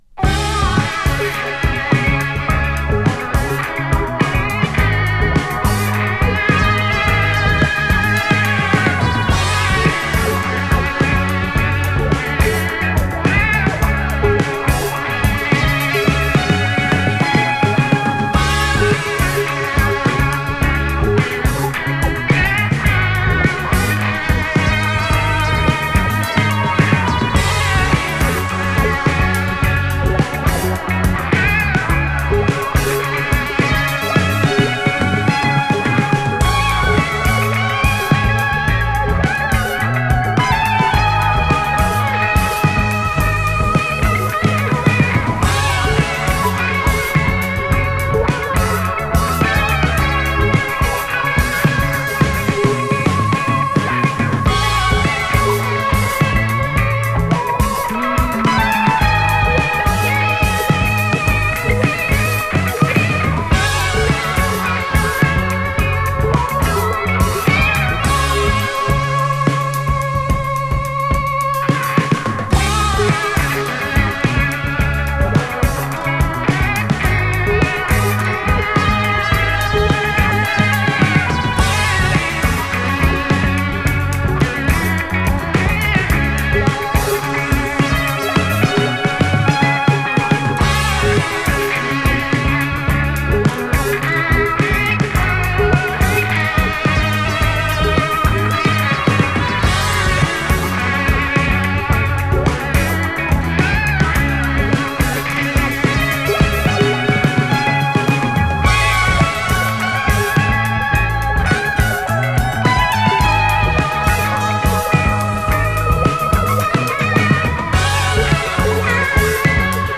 > PSYCHEDELIC/PROGRESSIVE/JAZZ ROCK